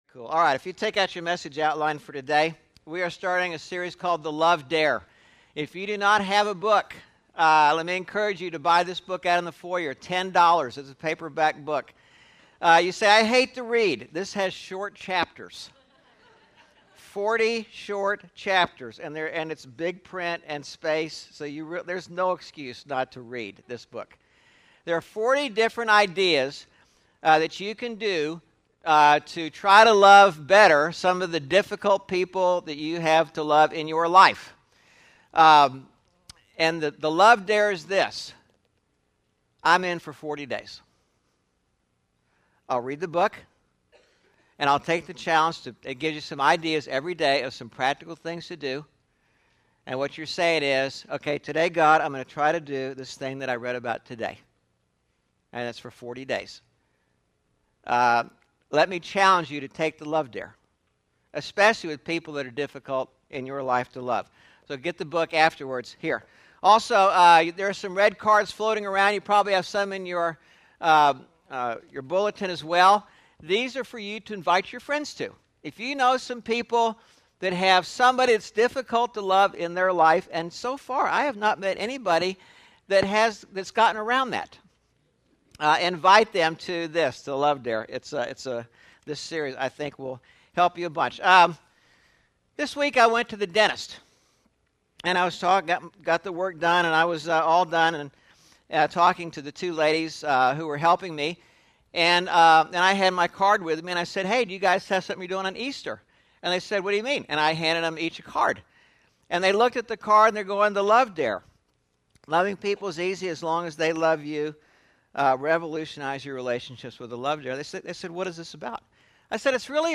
4/24/11 Easter sermon (The Love Dare Pt.1) – Churches in Irvine, CA – Pacific Church of Irvine